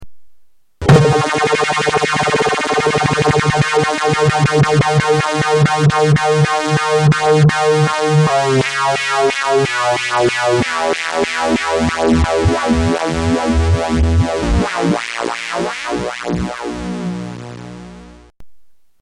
Tags: Sound Effects EML ElectroComp 101 EML101 ElectroComp 101 Synth Sounds